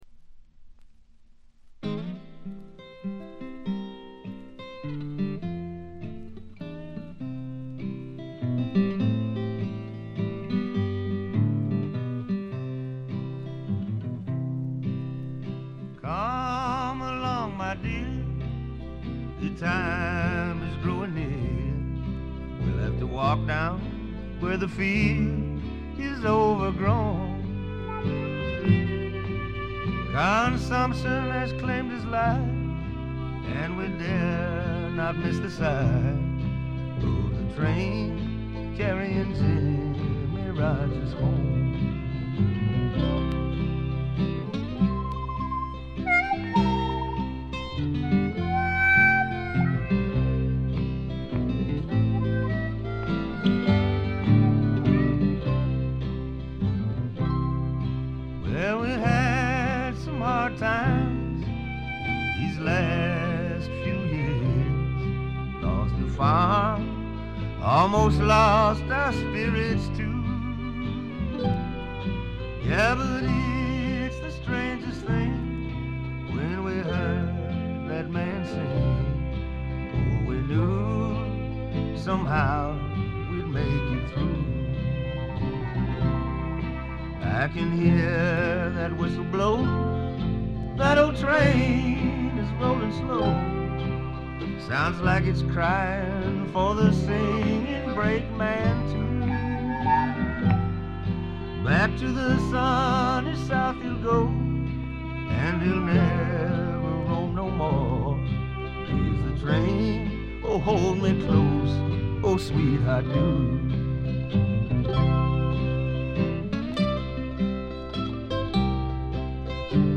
にがみばしった男の哀愁を漂わせたヴォーカルがまず二重丸。
試聴曲は現品からの取り込み音源です。